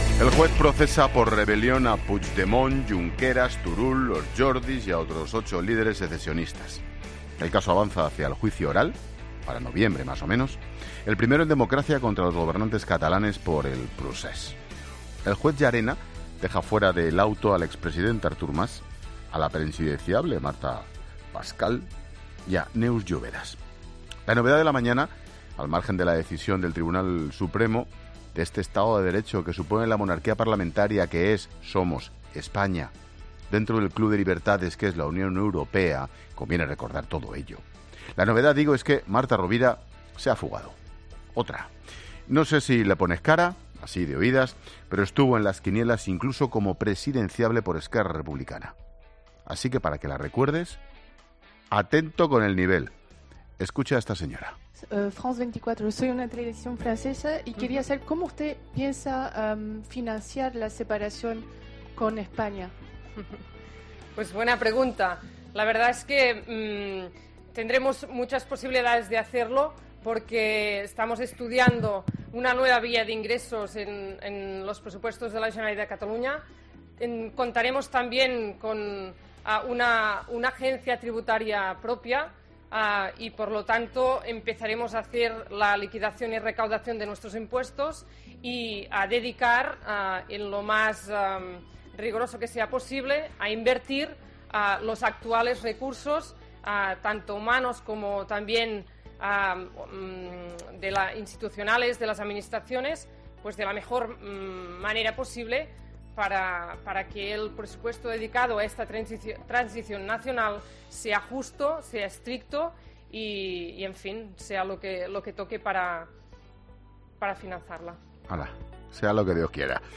Monólogo de Expósito
Comentario de Ángel Expósito sobre la situación de Cataluña